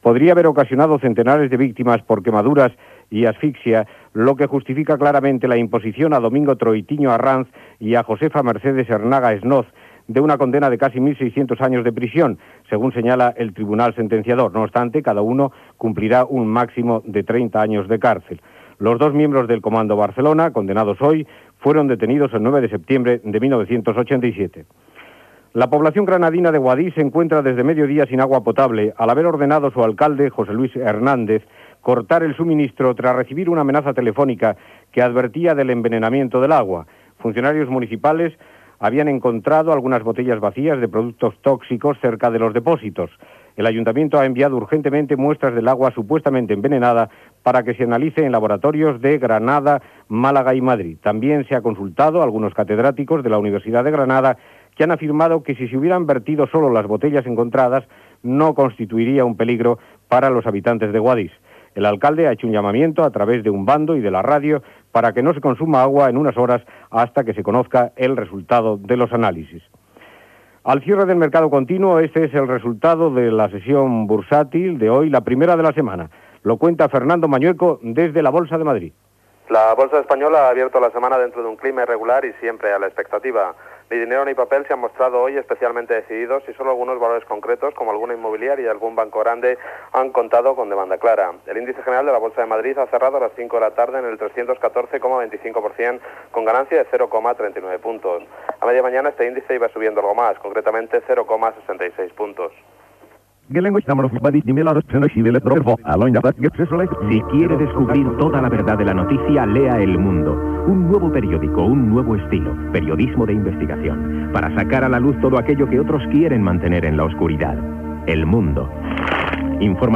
Butlletí informatiu de les 18.00 hores. Sentència a dos membres d'ETA, aigua enverinada a Guadix, la borsa, publicitat.
Informatiu